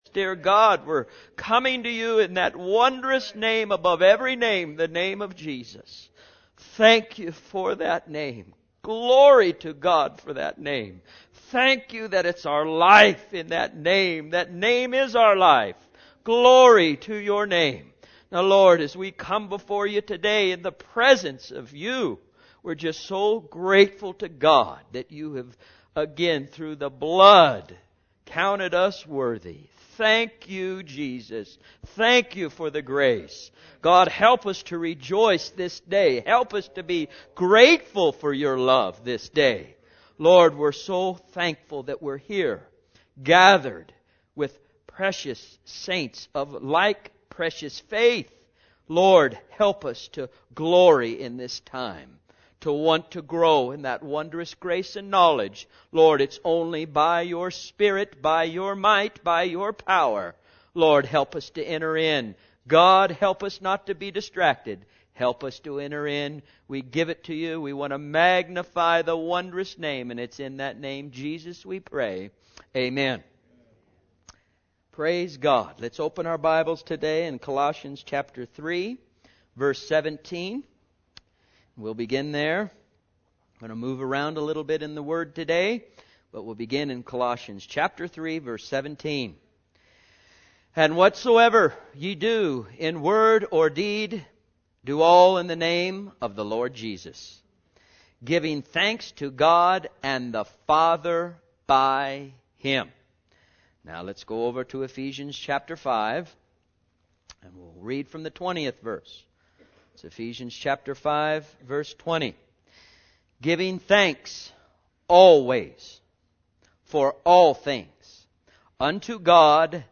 Sunday's Sermons for 2008